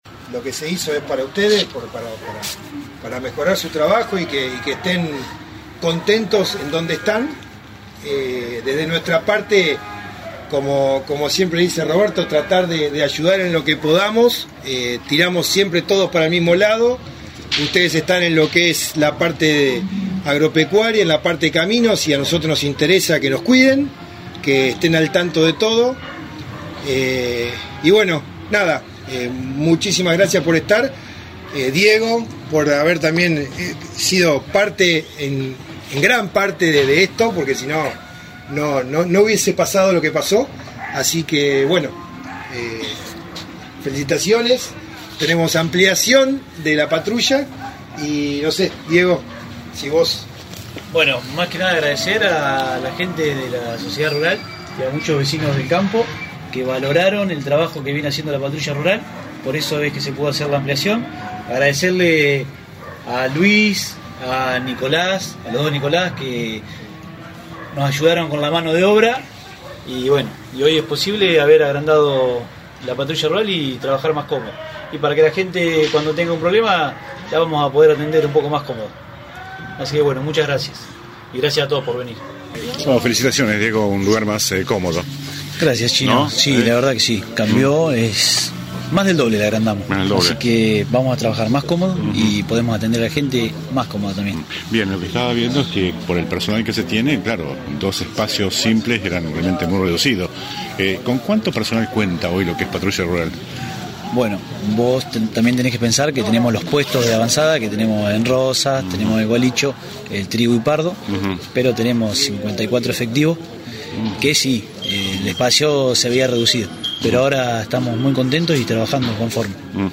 En un sencillo pero cálido acto, se llevó a cabo a las 19 hs. del dia de la fecha,  la ampliación del CPR ubicado en el predio de la SRLF.
AUDIO DE LA PRESENTACIÓN Y ENTREVISTA.